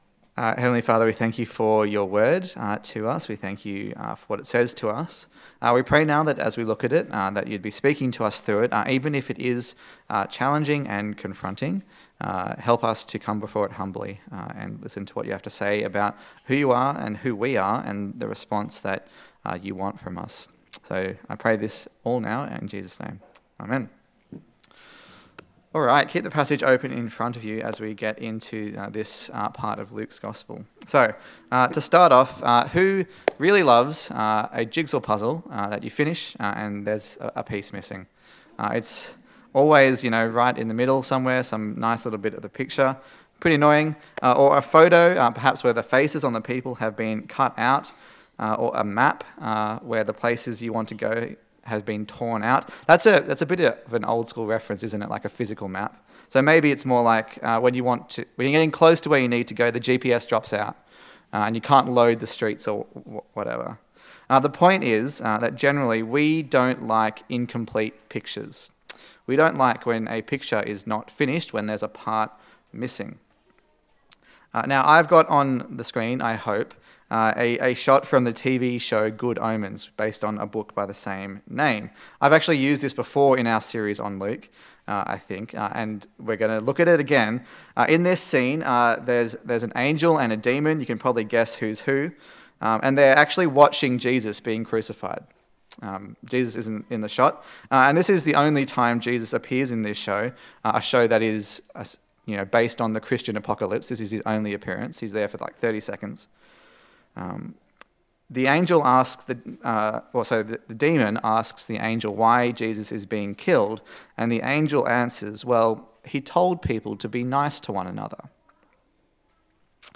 Luke Passage: Luke 12:49-13:9 Service Type: Sunday Service